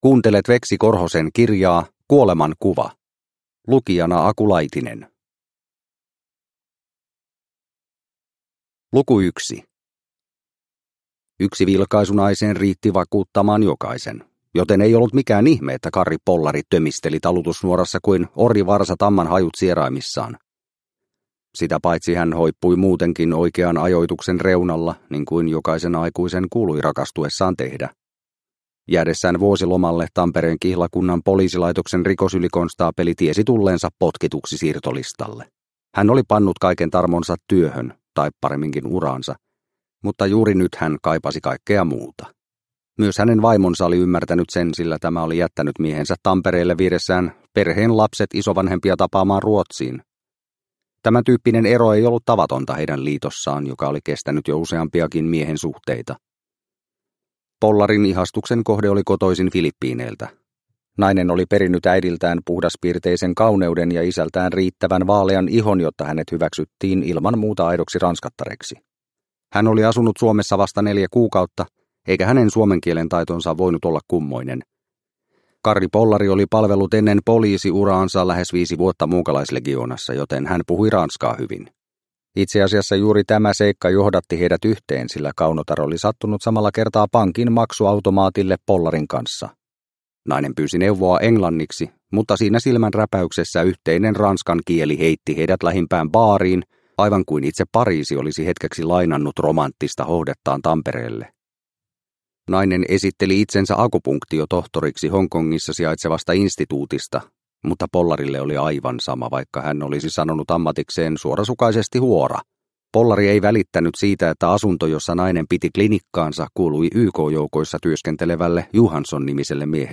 Kuoleman kuva – Ljudbok – Laddas ner